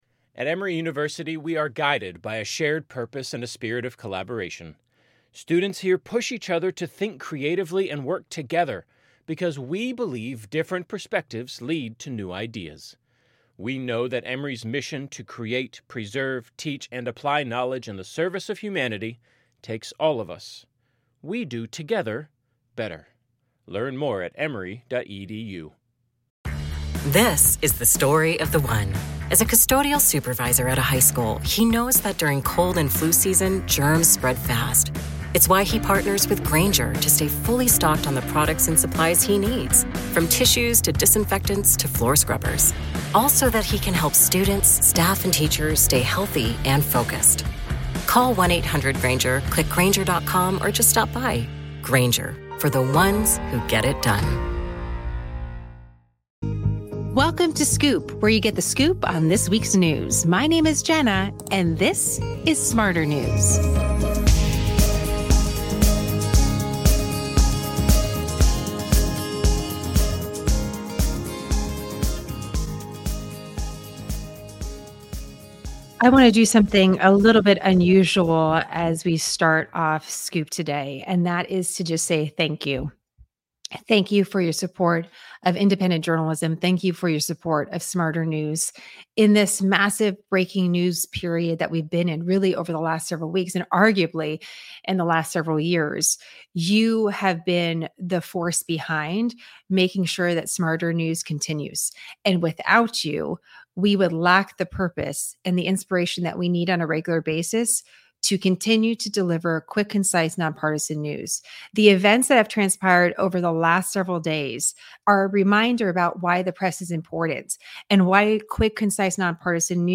As we wait for more information on the attempted assassination of former Pres. Trump, we hear from two very special guests: an eyewitness who attended Pres. Trump’s rally in Pennsylvania and a historian and journalist with incredible expertise on an important character in American history.